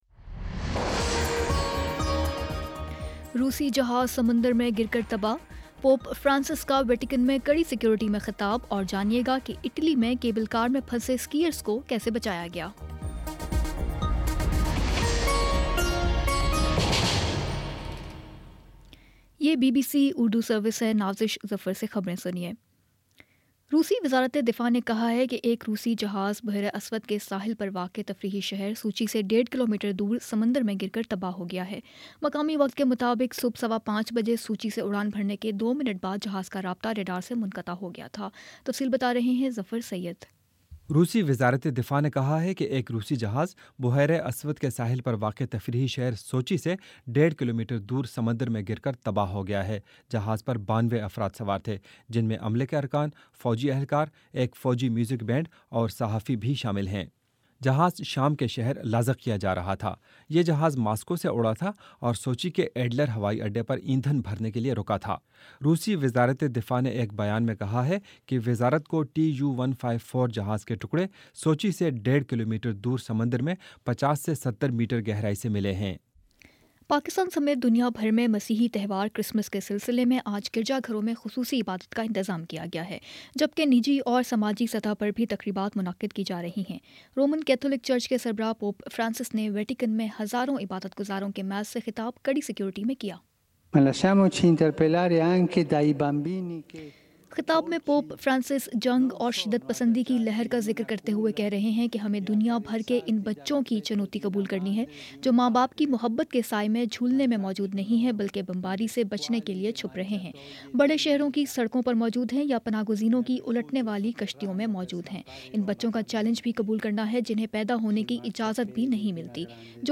دسمبر 25 : شام چھ بجے کا نیوز بُلیٹن